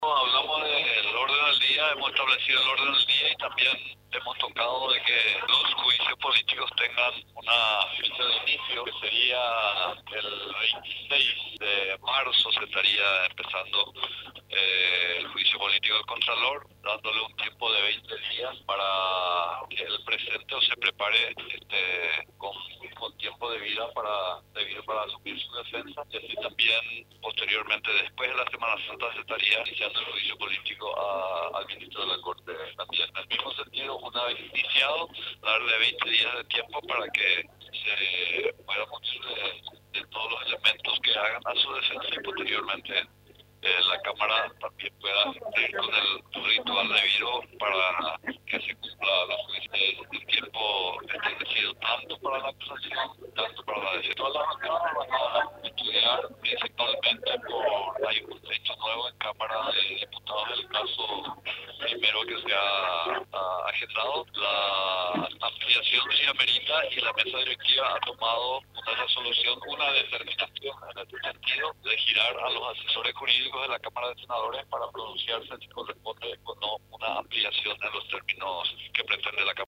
El primero para el Contralor General de la República, Enrique García y posteriormente para el ministro de la Corte Suprema, César Garay Zuccolillo, informó el Senador Juan Dario Monges.
21-JUAN-DARIO-MONGES-SENADOR-COLORADO.mp3